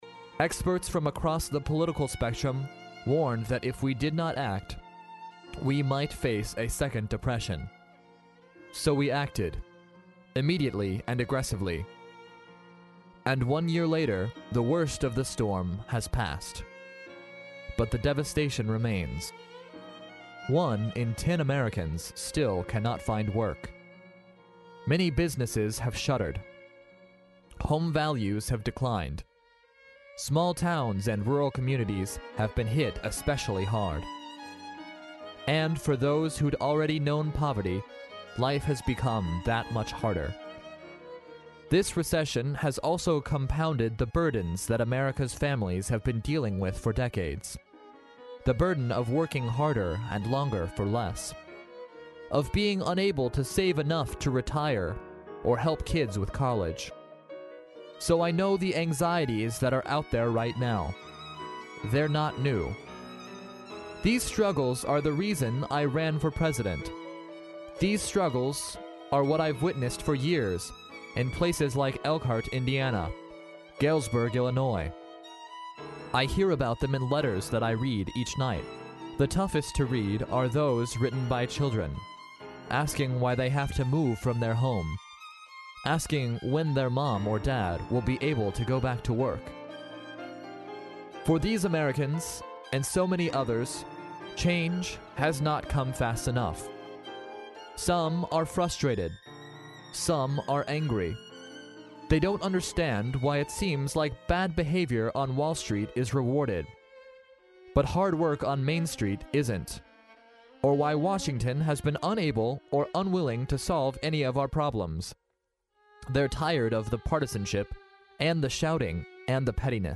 在线英语听力室历史英雄名人演讲 第62期:奥巴马总统国情咨文(2)的听力文件下载, 《历史英雄名人演讲》栏目收录了国家领袖、政治人物、商界精英和作家记者艺人在重大场合的演讲，展现了伟人、精英的睿智。